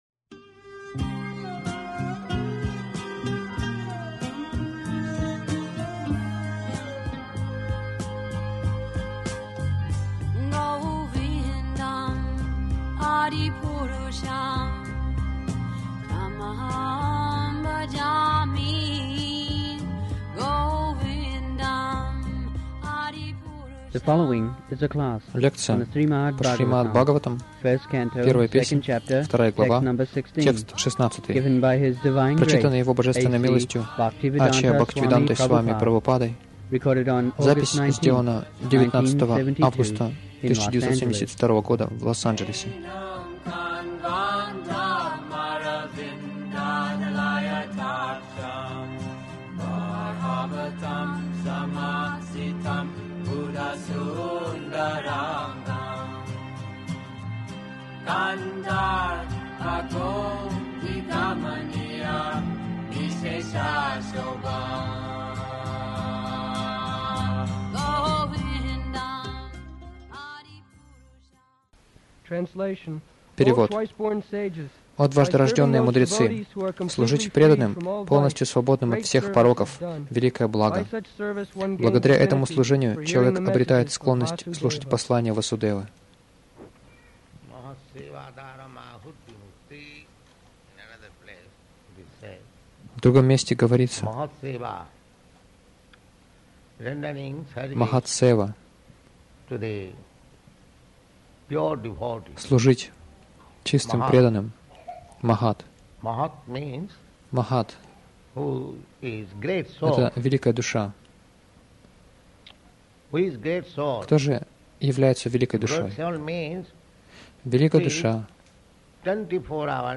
Милость Прабхупады Аудиолекции и книги 19.08.1972 Шримад Бхагаватам | Лос-Анджелес ШБ 01.02.16 — Путь возвращения к Богу Загрузка...